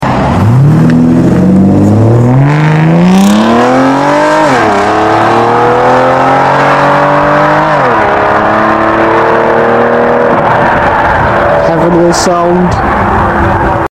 Aston Martin V12 Is Music Sound Effects Free Download